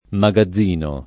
magazz&no] s. m. — d’uso region. (settentr.), e solo nel sign. proprio di «deposito» (non nei sign. fig. di «emporio» o di «rivista»), magazzeno [magazz$no] — sim. i top. Magazzeno, -ni, Magazzino, -ni (Sic., ecc.) e il cogn. Magazzini